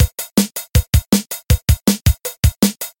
・ROM 3 80 年代のドラムサウンドをカスタム